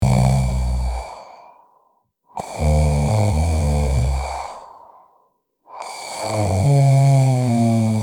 A Person Snoring Sound Button - Free Download & Play